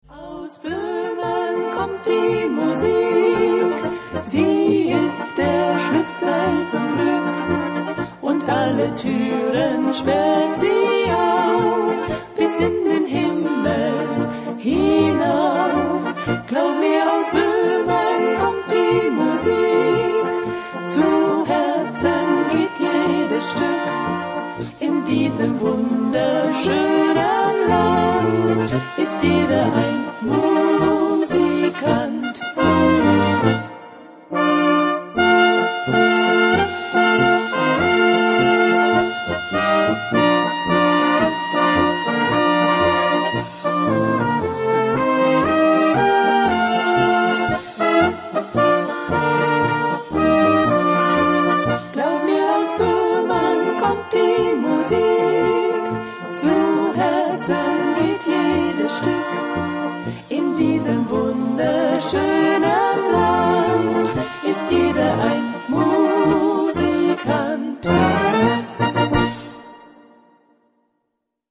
Gattung: Polkalied
Besetzung: Blasorchester